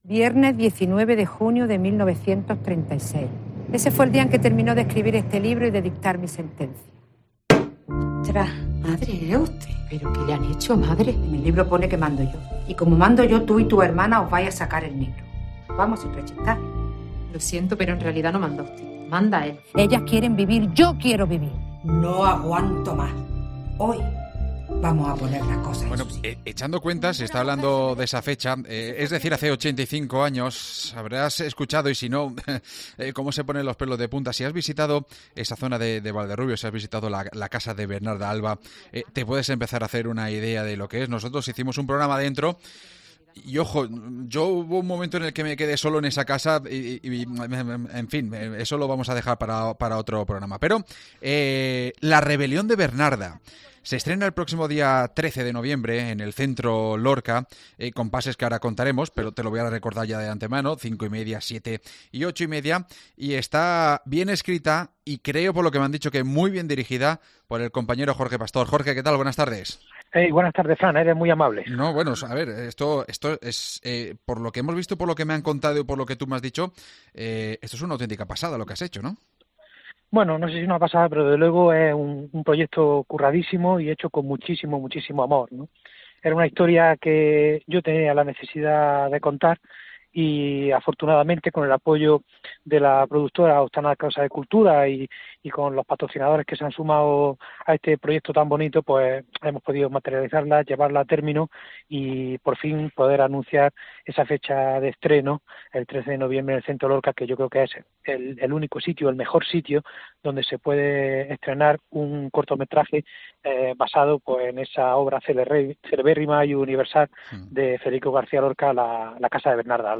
Hablamos con su director y con la actriz protagonista